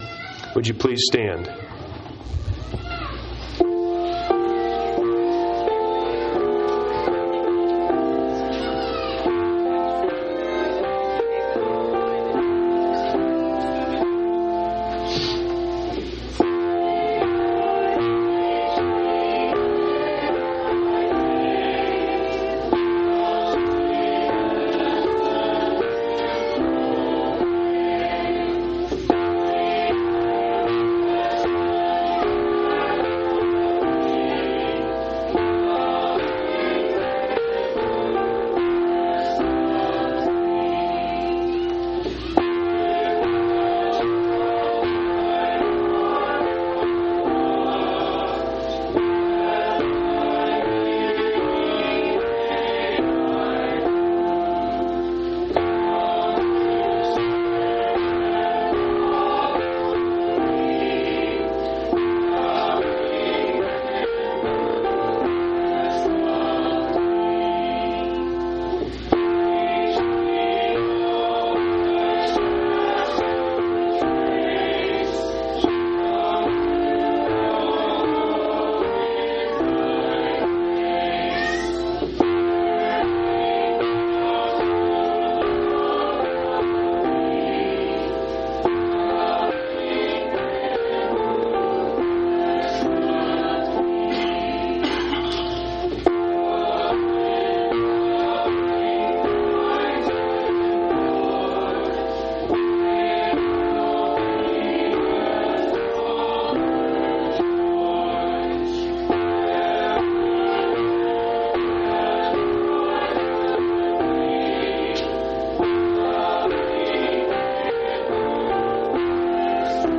6/22/2003 Location: Phoenix Local Event